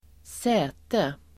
Uttal: [²s'ä:te]